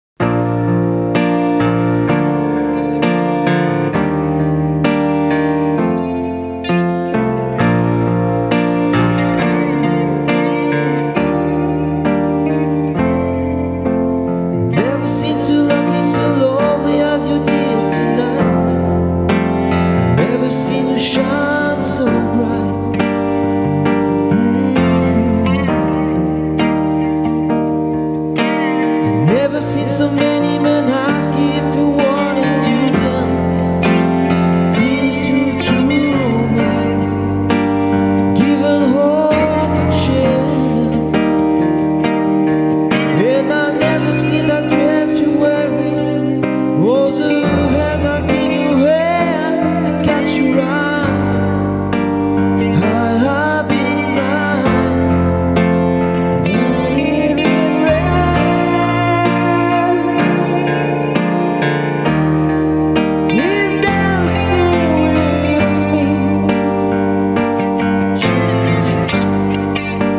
Le 05 et 06/08/2006 au studio "Mafaldine", Lamalou, France
Guitare
Claviers, Chant, Choeurs